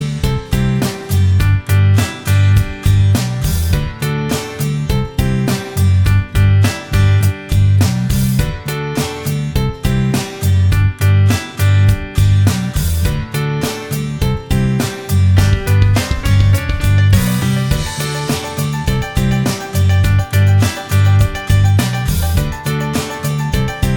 Minus Guitars Pop (2010s) 4:44 Buy £1.50